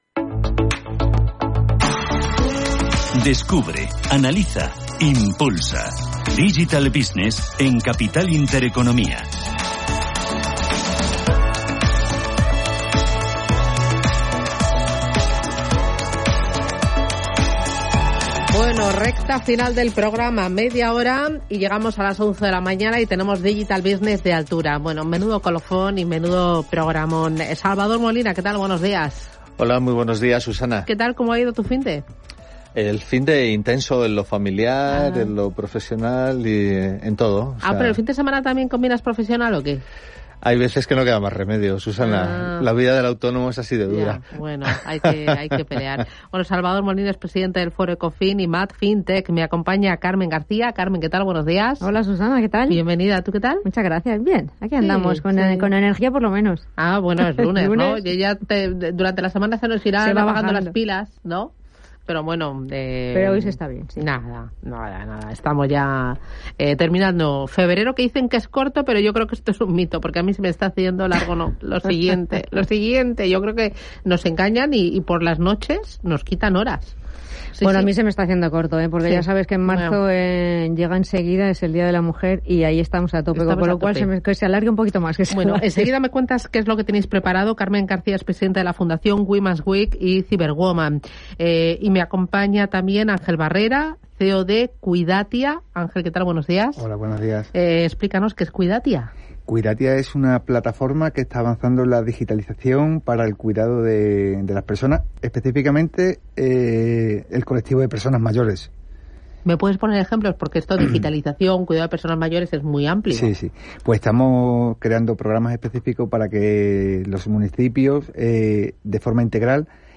El clúster Madrid Capital FinTech (MAD FinTech) y la startup mexicana AurumCore lo anunciaron en Intereconomía Radio: ha llegado el primer Sandbox privado a España. Se trata de un espacio de pruebas a proyectos y empresas FinTech y financieras que quieran testar su modelo de negocio en un entorno seguro y controlado que no requerirá burocracia ni inversión en tecnología, porque todo será proporcionado por ambas entidades.